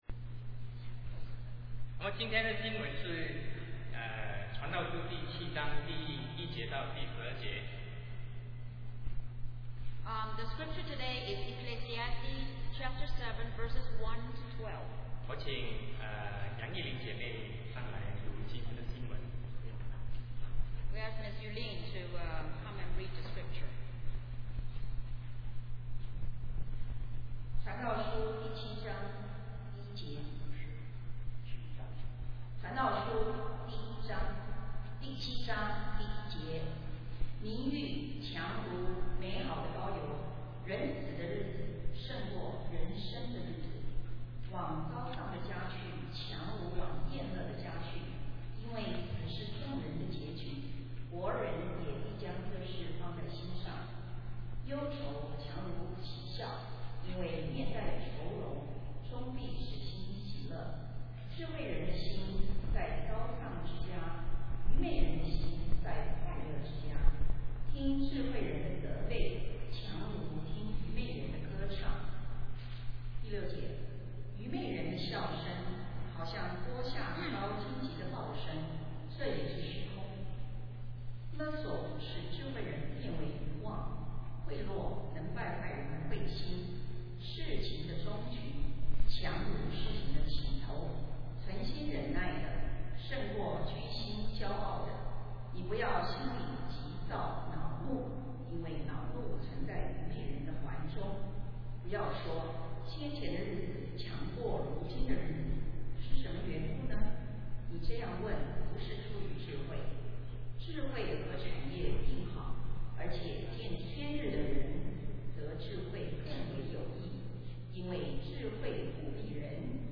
Sermon 2007-12-09 The Values of Life